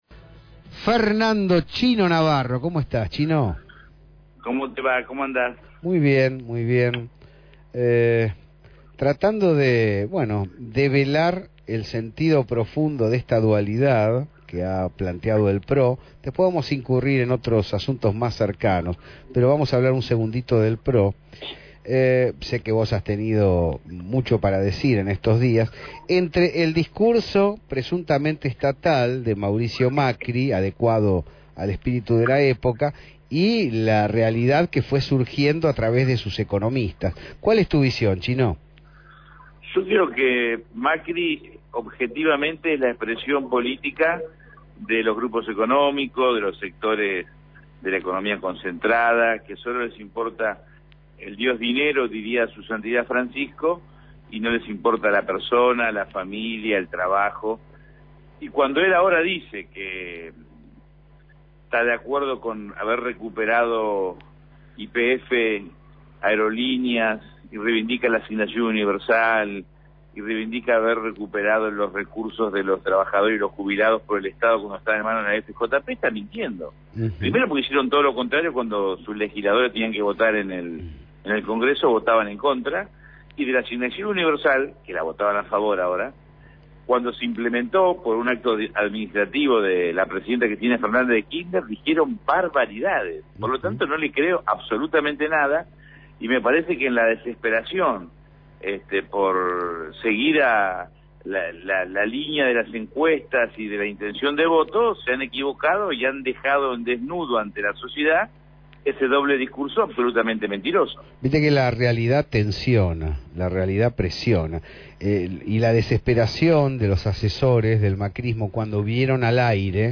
Así lo expresó Fernando Chino Navarro, entrevistado